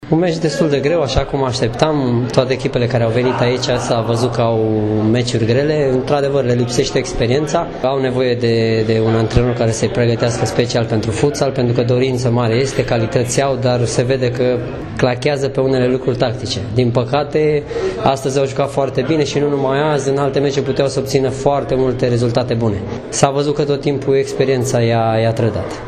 Ascultaţi declaraţiile antrenorilor în urma acestei partide aprig disputate